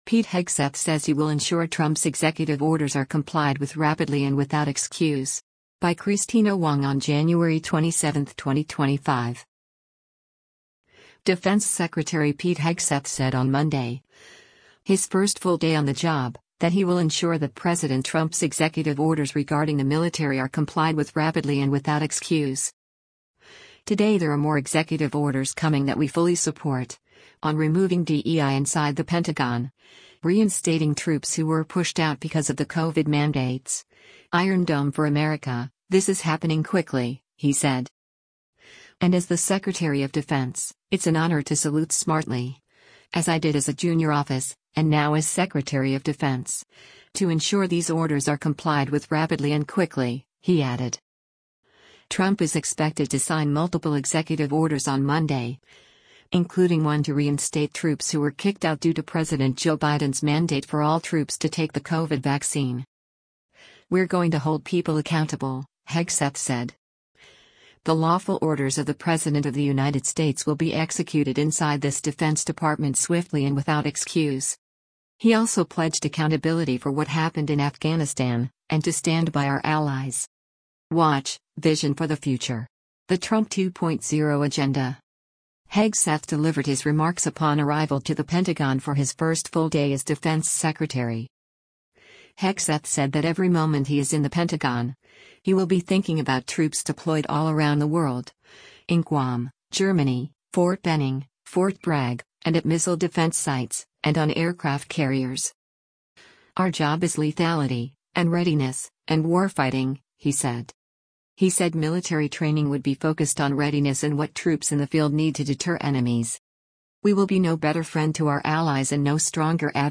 Hegseth delivered his remarks upon arrival to the Pentagon for his first full day as defense secretary.